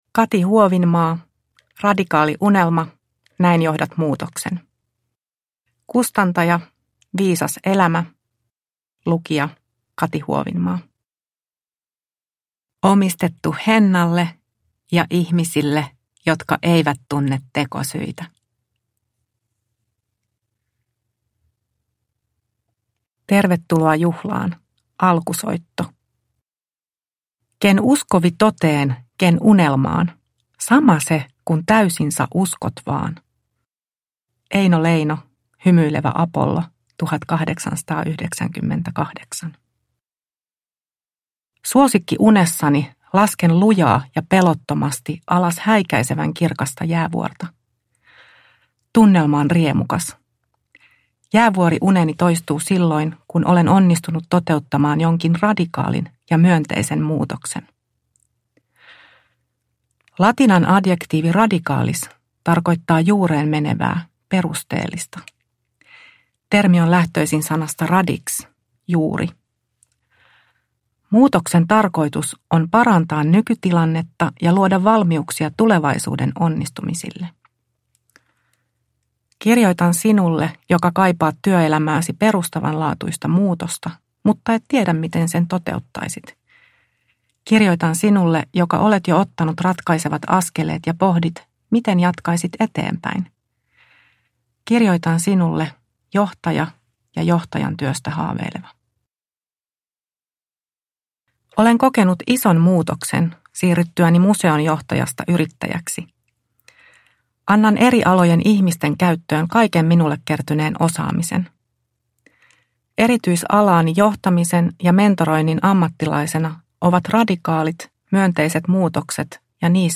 Radikaali unelma – Ljudbok – Laddas ner